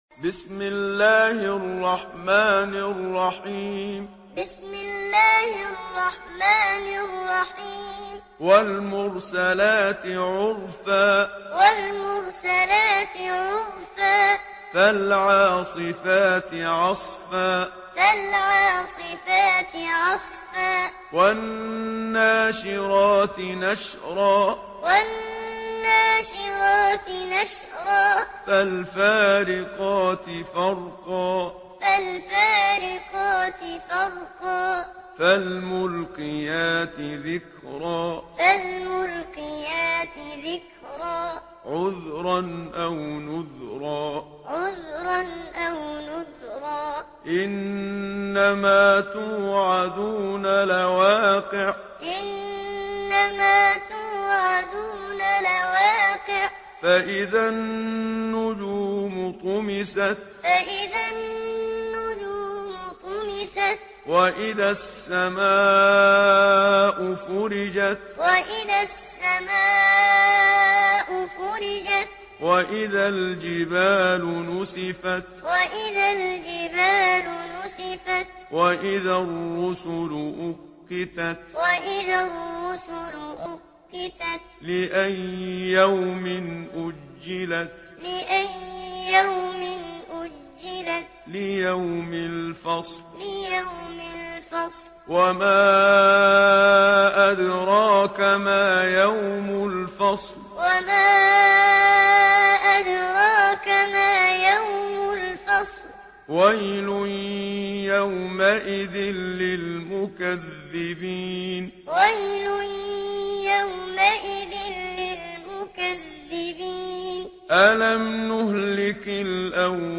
Surah المرسلات MP3 by محمد صديق المنشاوي معلم in حفص عن عاصم narration.